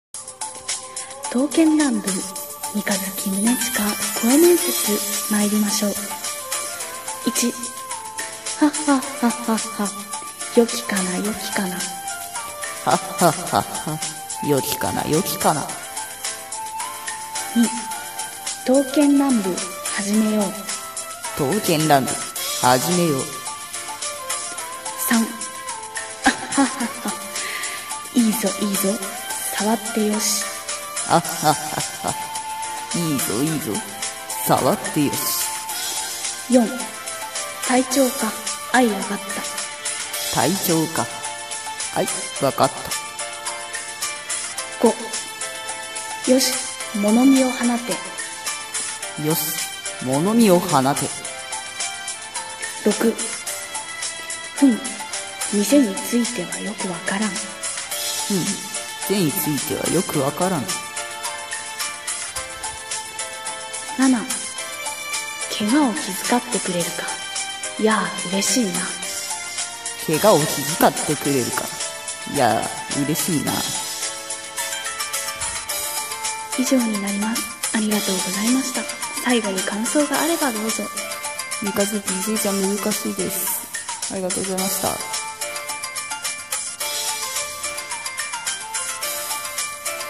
刀剣乱舞 三日月宗近 声面接